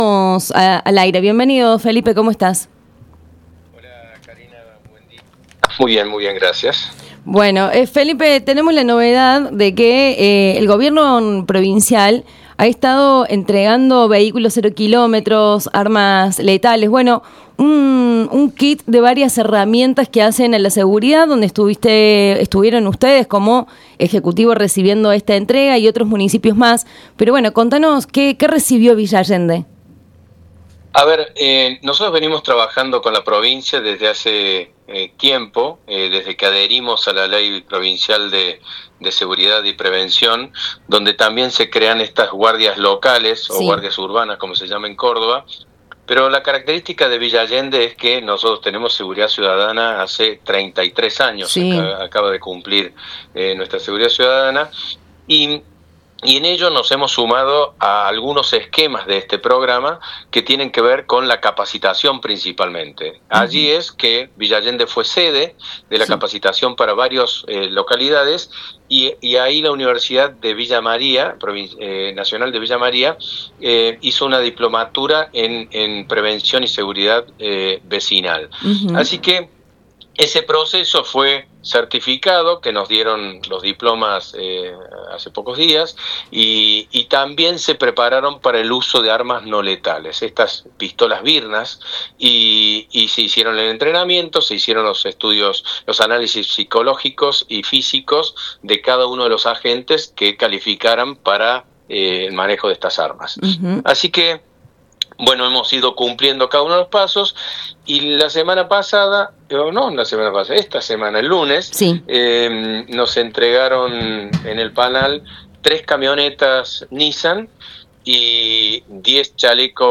ESCUCHA LA ENTREVISTA COMPLETA A FELIPE CRESPO EN ANEXADOS
El secretario de Gobierno de Villa Allende, Felipe Crespo, dialogó con Radio Nexo sobre distintos temas vinculados a la gestión local, entre ellos la entrega de equipamiento para la seguridad, el uso de drones en tareas de monitoreo, los problemas de convivencia en distintos barrios y el estado del registro provincial de la reserva Supaj Ñuñu, temas que atraviesan la agenda pública de la ciudad.